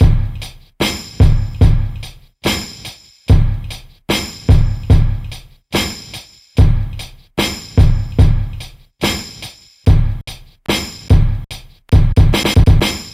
Okaga Drum Break 73bpm.wav